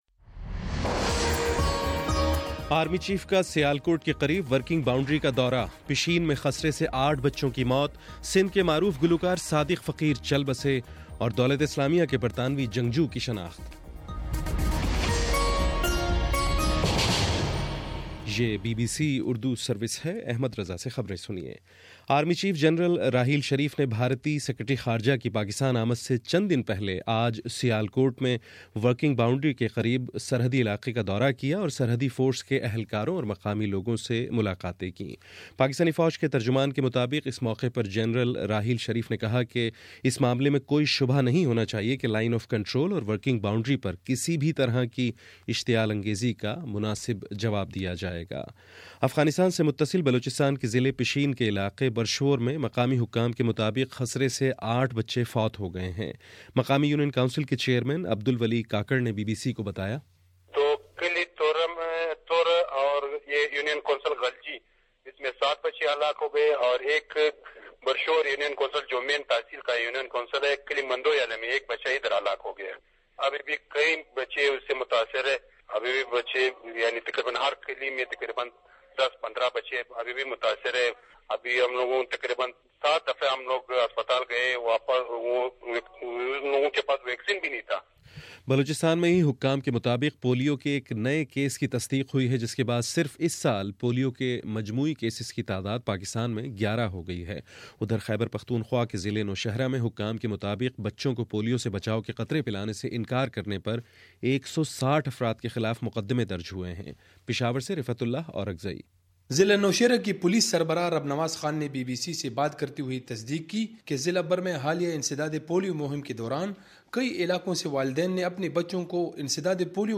فروری26: شام سات بجے کا نیوز بُلیٹن